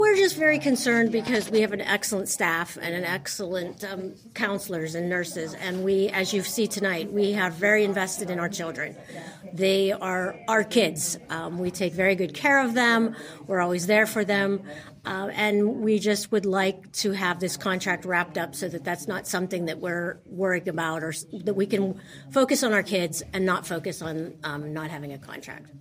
Thursday night, the Homer-Center School Board heard from members of the Homer-Center Education Association about the ongoing contract negotiations.